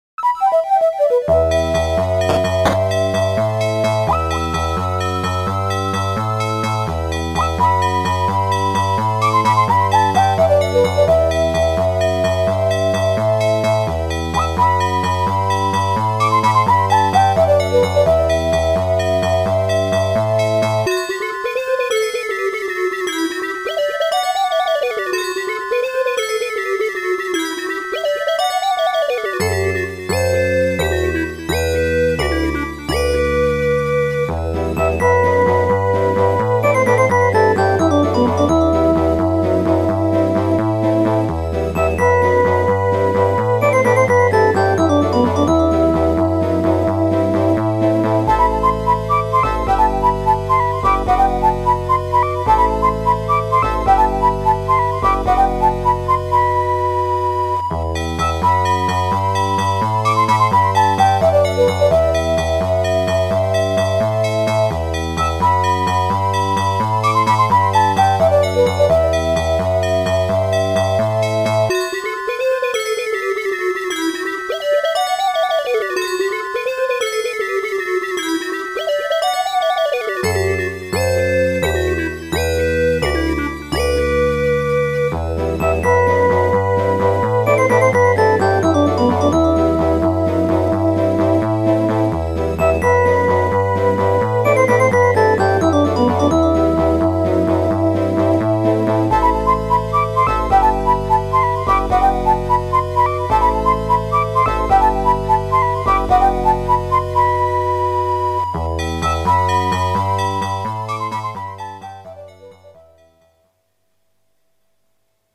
A cheerful FM synth retro game chiptune with interesting variation.